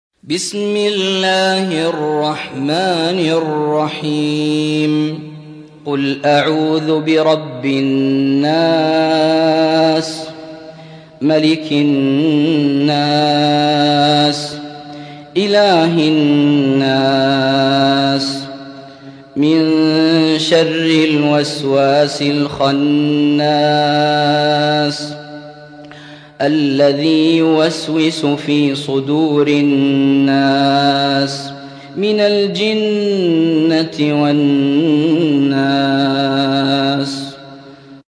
سورة الناس / القارئ